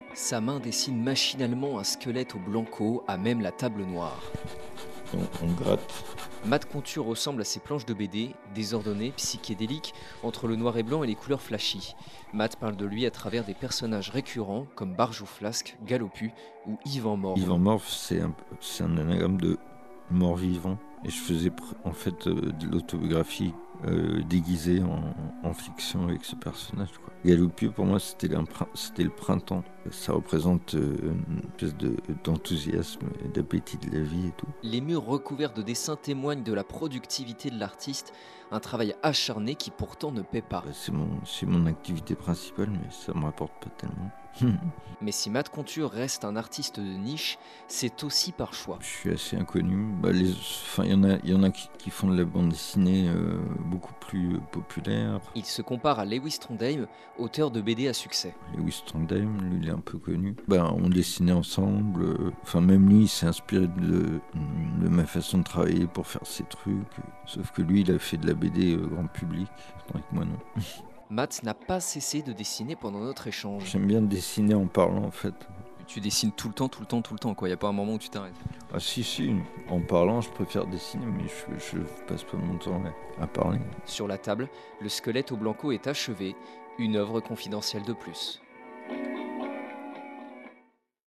Sa voix est faible et rauque